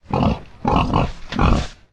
boar_idle_0.ogg